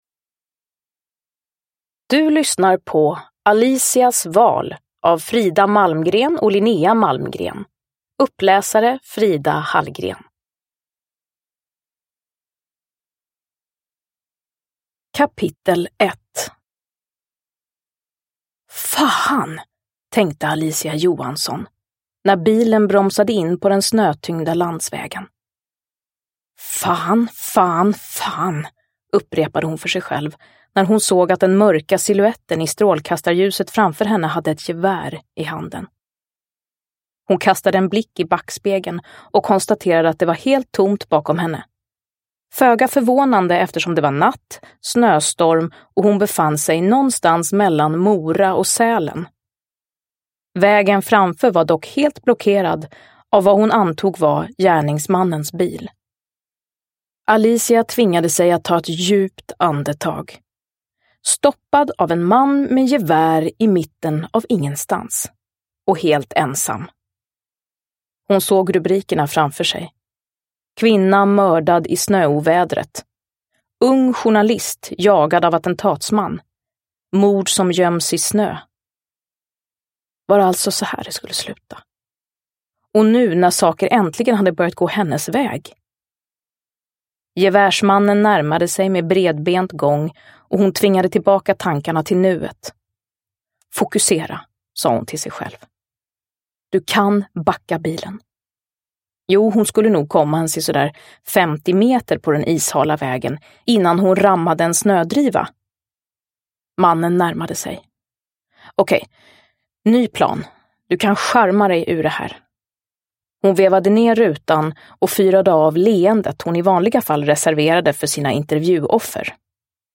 Uppläsare: Frida Hallgren
Ljudbok